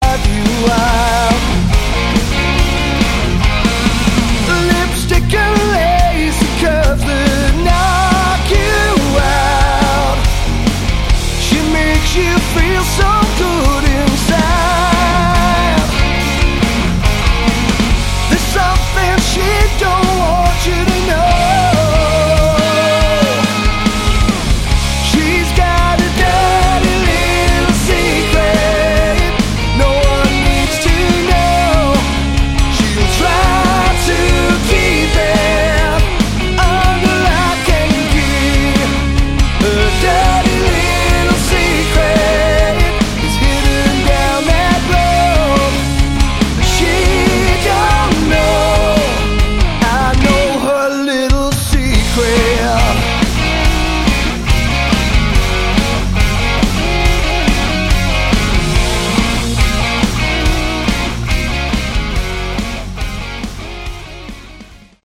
Category: Hard Rock
lead and backing vocals, bass, guitars
electric, acoustic and slide guitars
drums
kayboards, backing vocals